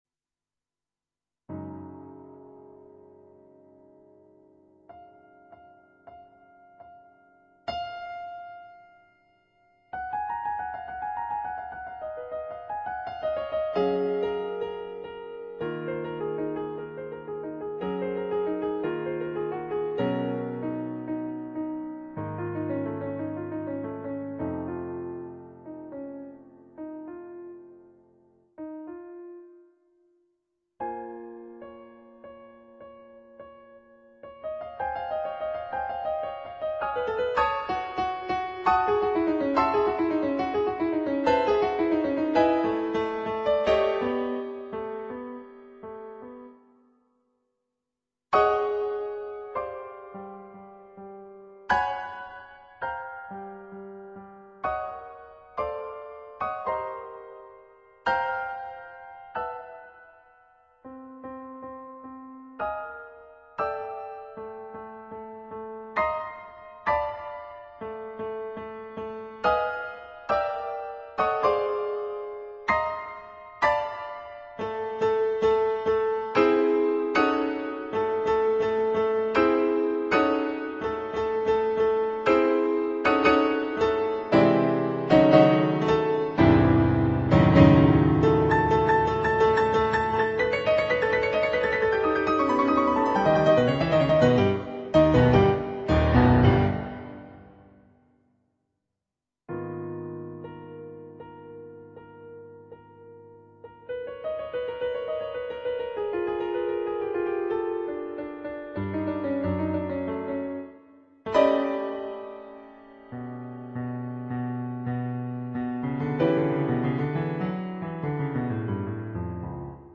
Andante poco rubato (2'53")
on Yamaha digital pianos.